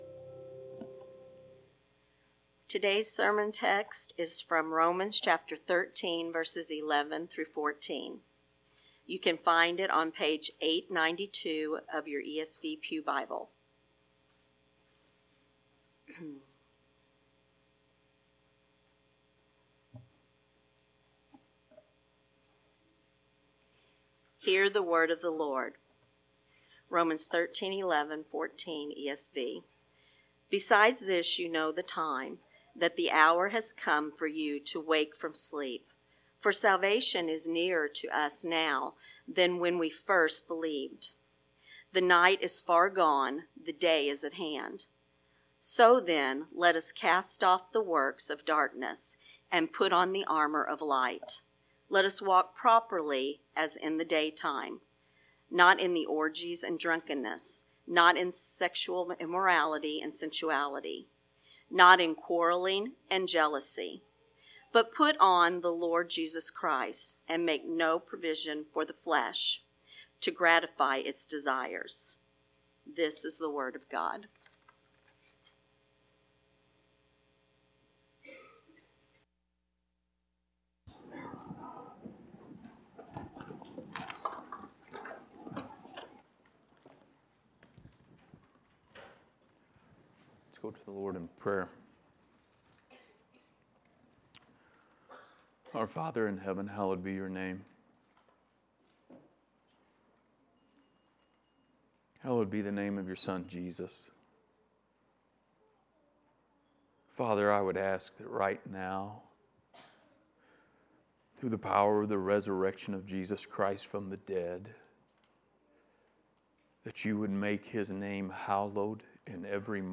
Passage: Romans 13:11-14 Service Type: Sunday Morning